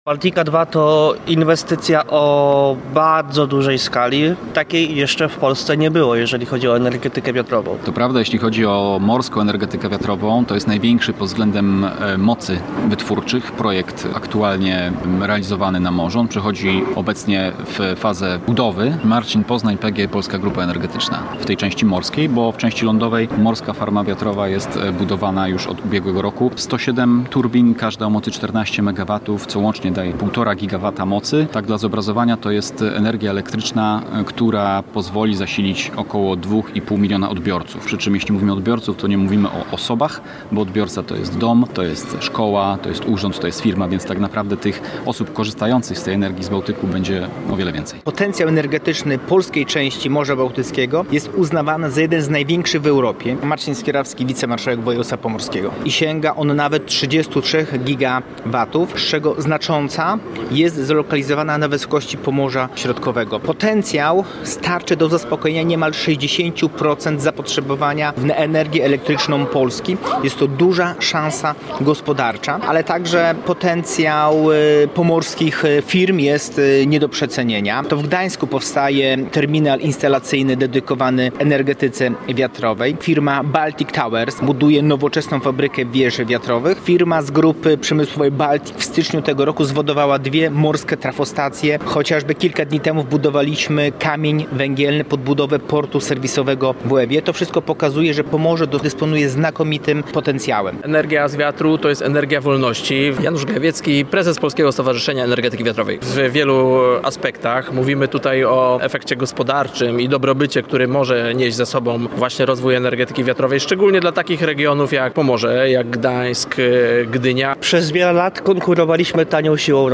Morska energetyka wiatrowa to miejsca pracy, rozwój gospodarczy i czysta energia. W Gdańsku trwa wydarzenie Baltic Sea Offshore Wind Summit.